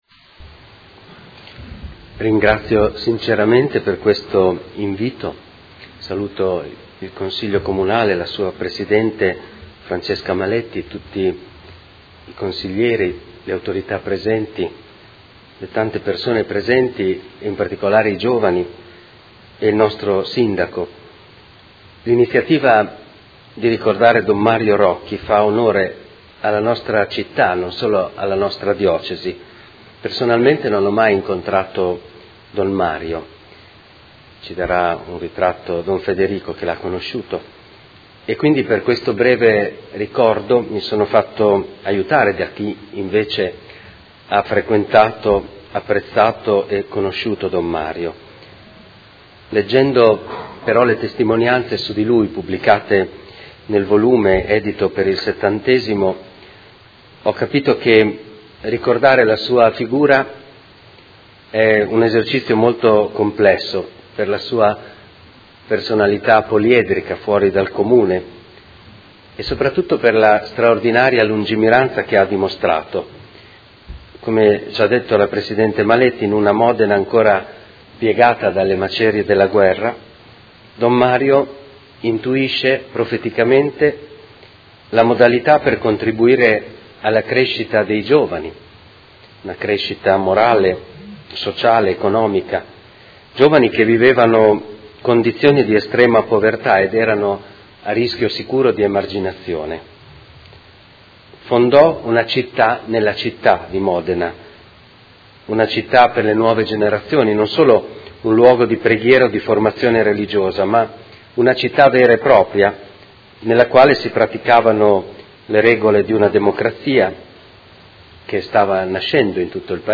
Seduta del 19/01/2017 CITTA’ DEI RAGAZZI A 70 ANNI DALLA SUA NASCITA il Consiglio Comunale di Modena la ricorda insieme al suo fondatore Mons. MARIO ROCCHI